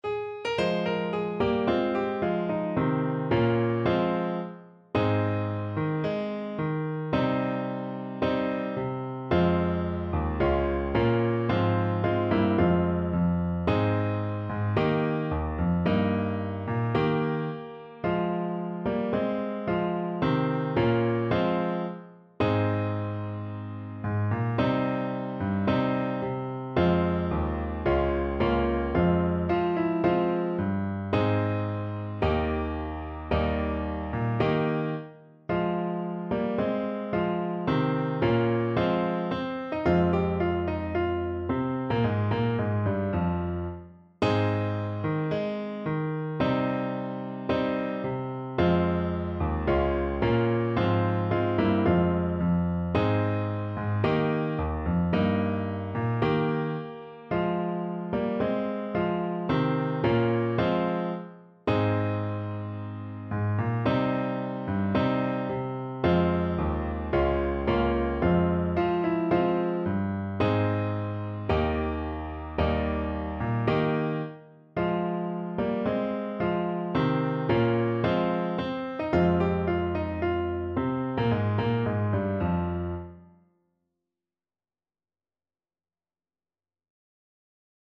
~ = 110 Allegro (View more music marked Allegro)
4/4 (View more 4/4 Music)